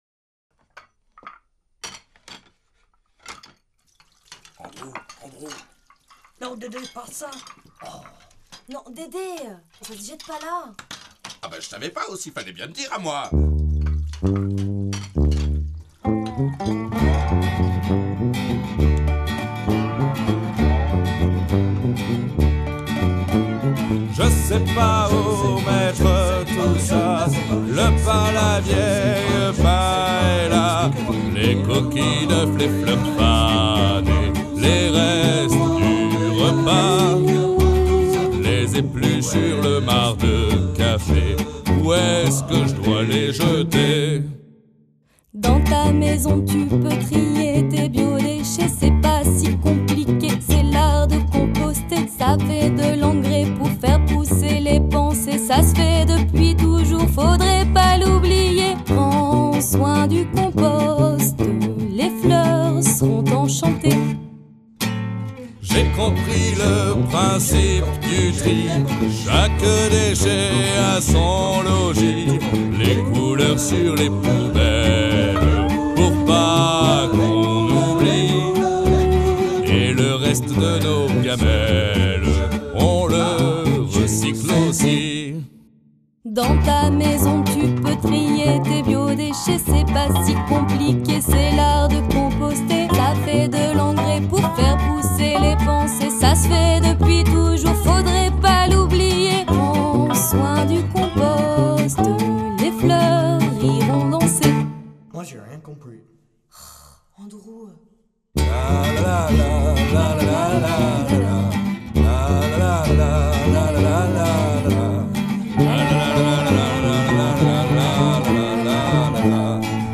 anniversaire du composteur Eugénie/Buisson juin 2019